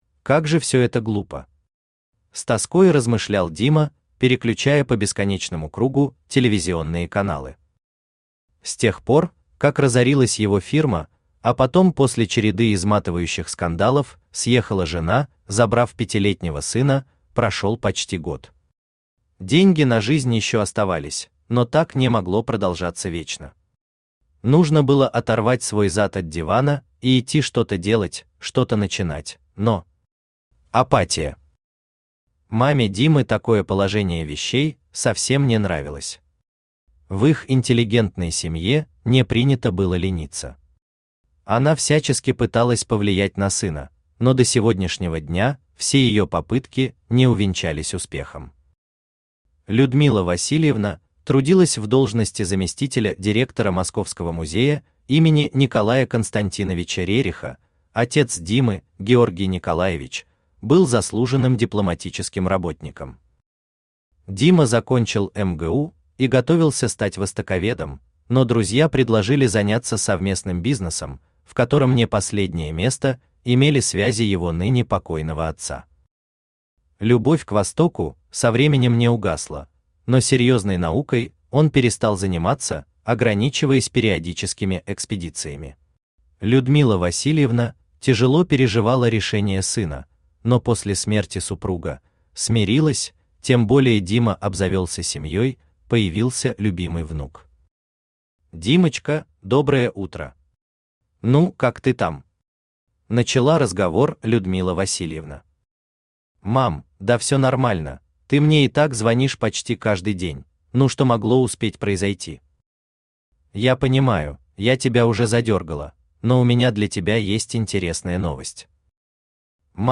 Аудиокнига Из тьмы | Библиотека аудиокниг
Aудиокнига Из тьмы Автор Михаил Витальевич Хенох Читает аудиокнигу Авточтец ЛитРес.